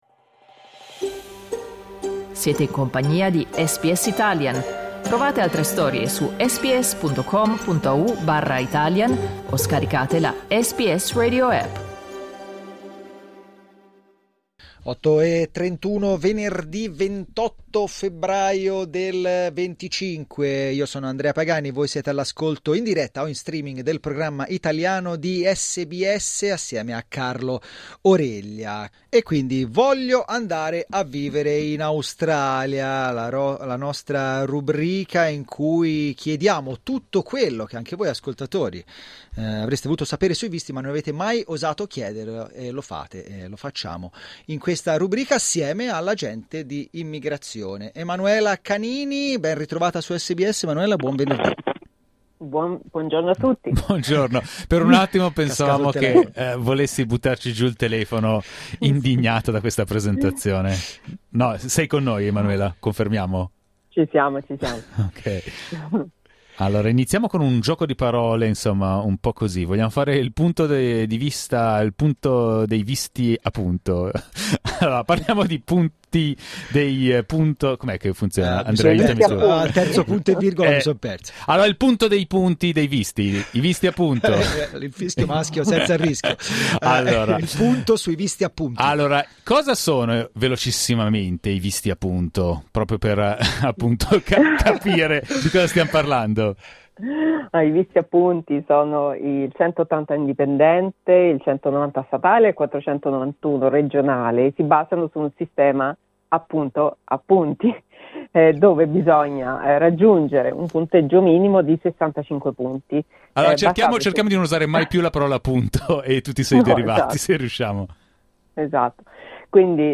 Ascolta l'intervista cliccando il tasto ‘play’ in alto a sinistra Ascolta SBS Italian tutti i giorni, dalle 8am alle 10am.